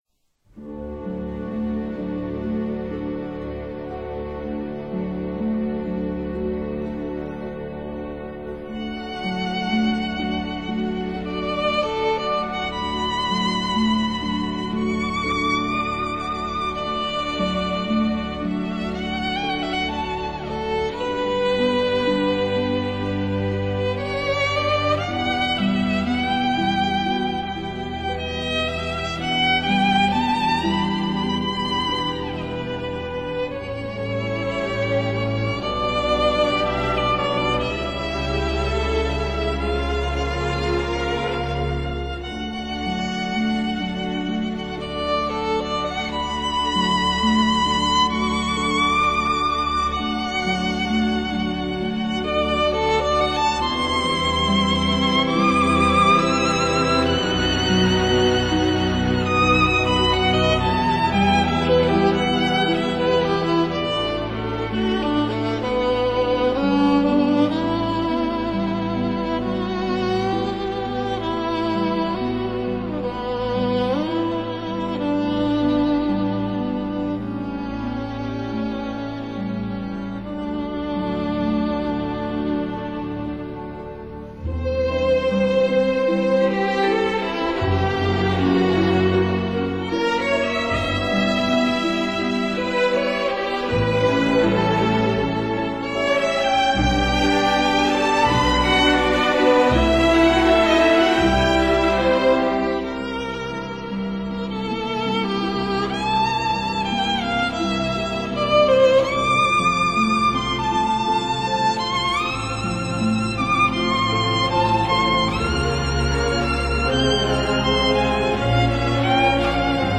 類型： 古典， 歌劇
violin